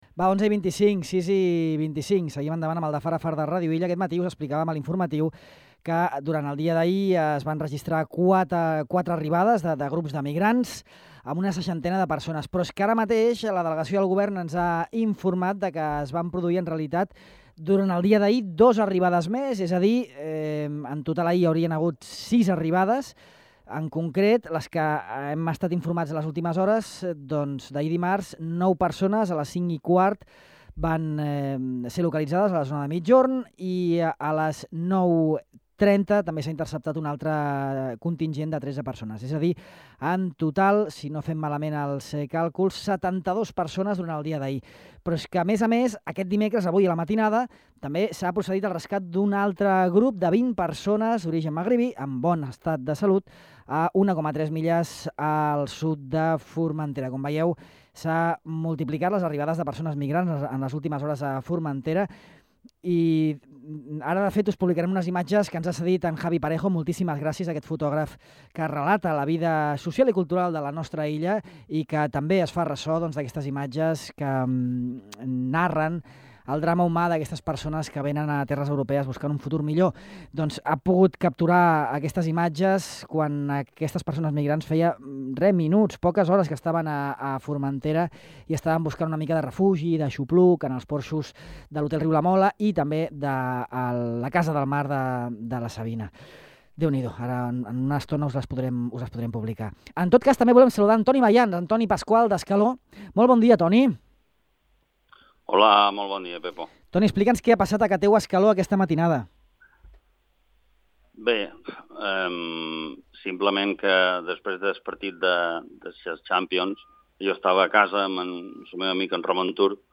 explica en aquesta entrevista a Ràdio Illa